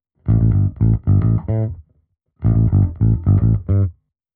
Index of /musicradar/dusty-funk-samples/Bass/110bpm
DF_JaBass_110-A.wav